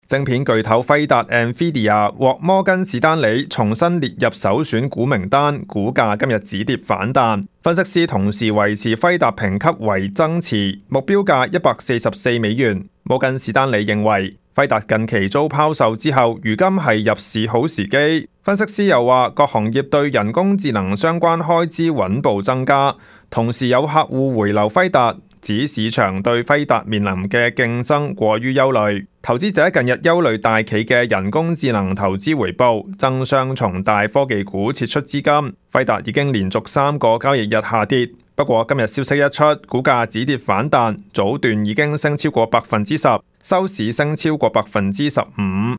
news_clip_19984.mp3